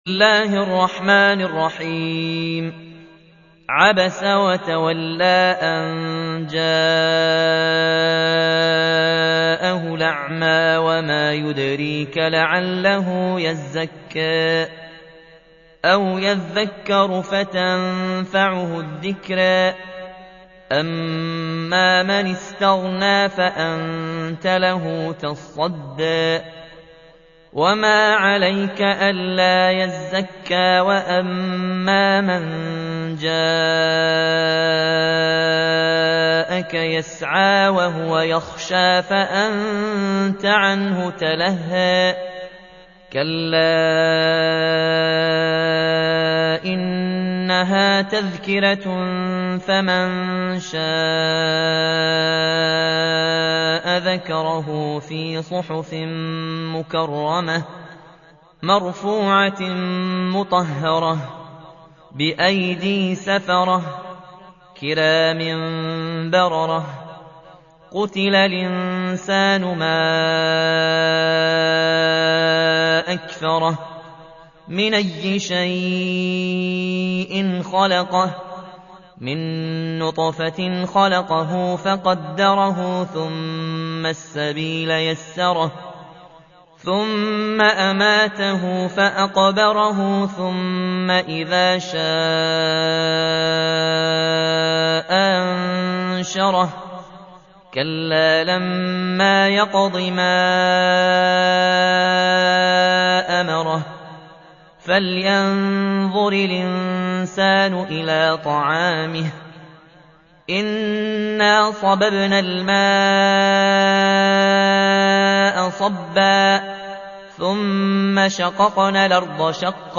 80. سورة عبس / القارئ